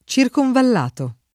circonvallato [ © irkonvall # to ]